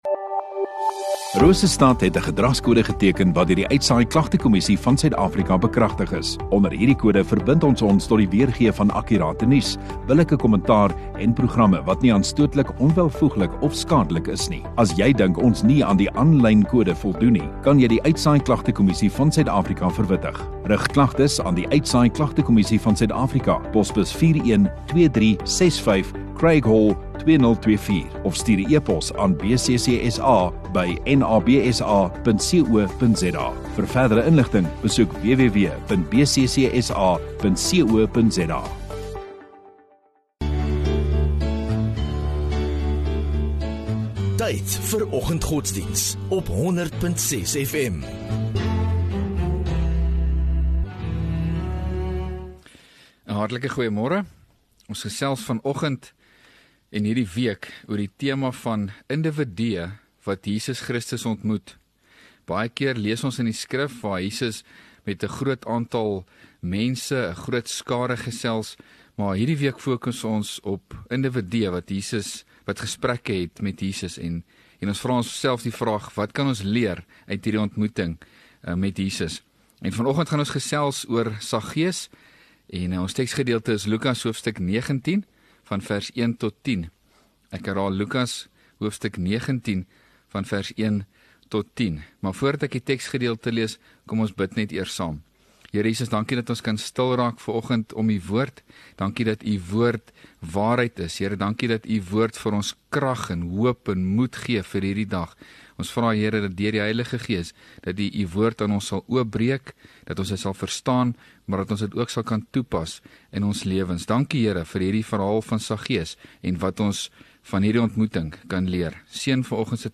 11 Sep Donderdag Oggenddiens